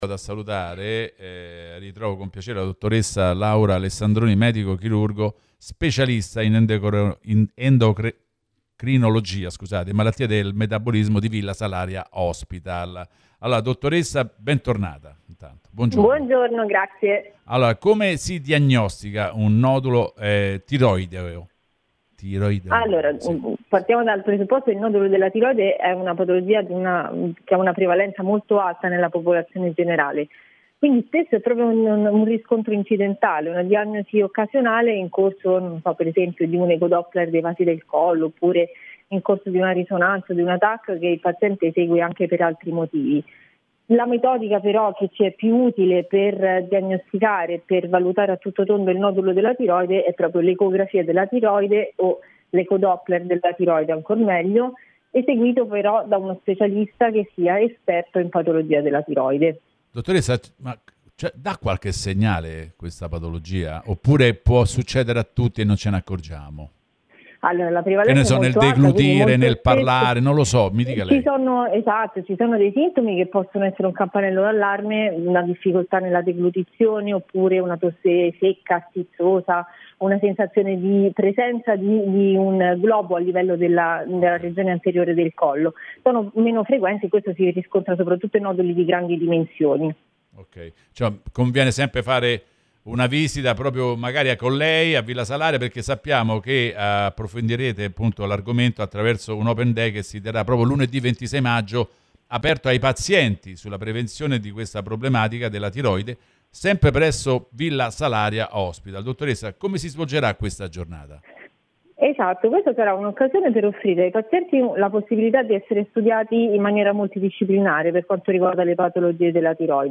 Approfondimento
Intervista